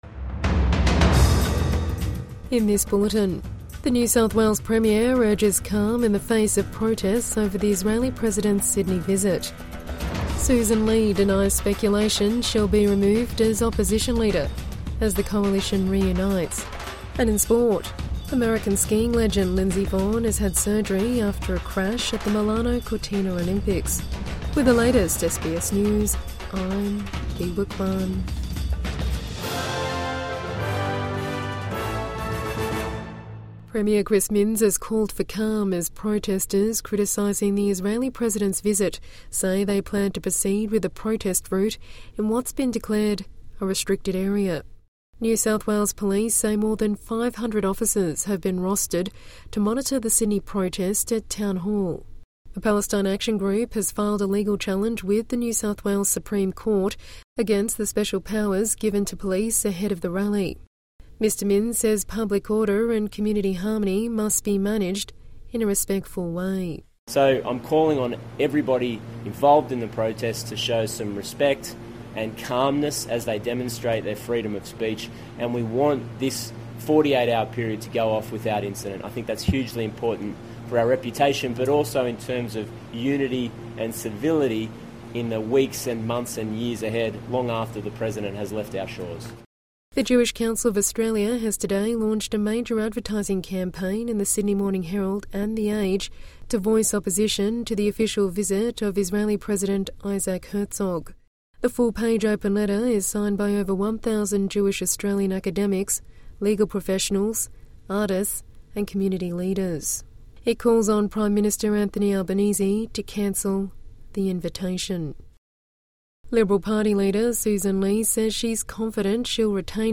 Premier Minns urges calm over protest against Herzog visit | Midday News Bulletin 9 February 2026